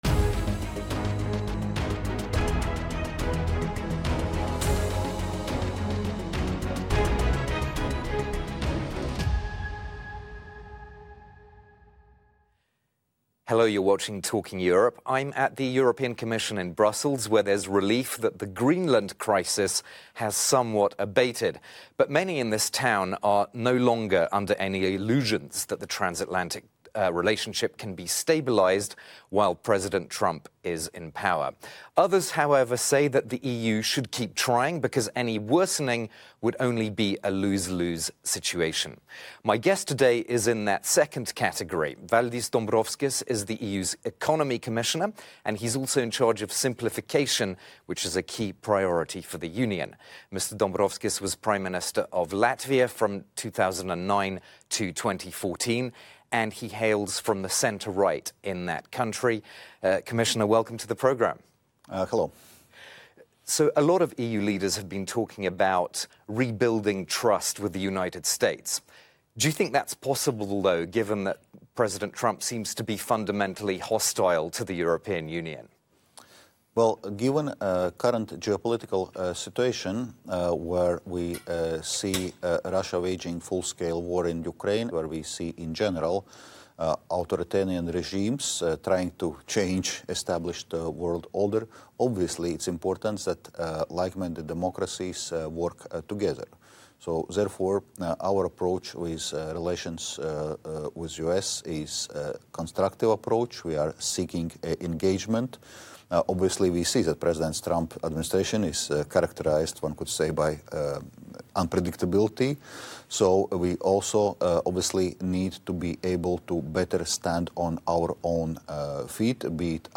As Europe faces unprecedented geopolitical headwinds, from the Ukraine war to Donald Trump’s designs on Greenland, we speak to the EU Commissioner in charge of the economy, productivity, and simplification: Valdis Dombrovskis.